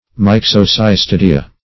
Search Result for " myxocystodea" : The Collaborative International Dictionary of English v.0.48: Myxocystodea \Myx`o*cys*to"de*a\, n. pl.